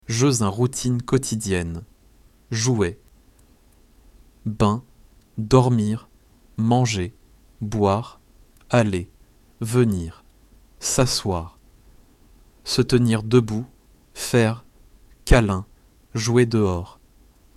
Lesson 4